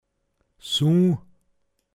pinzgauer mundart
Suu(n), m. Sohn